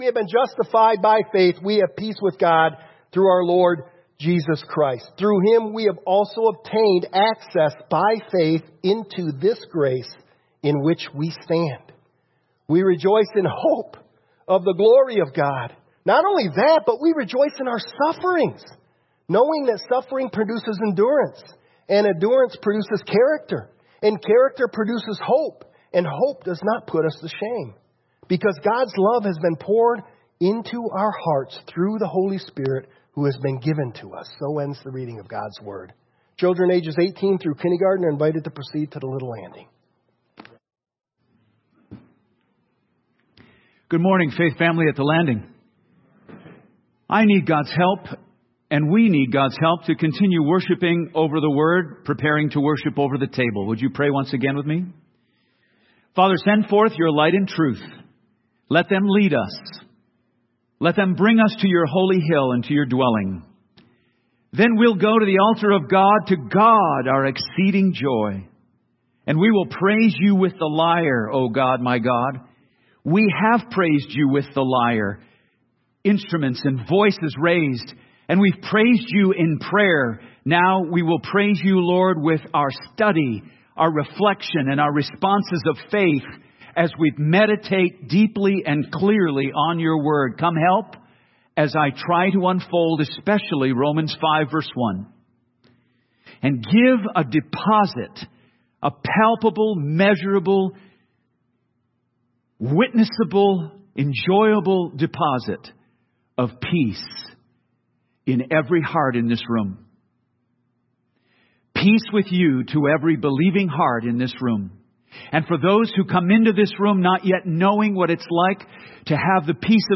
Download Download Reference Romans 5:1-5 Romans Current Sermon Through Christ We Have Peace With God!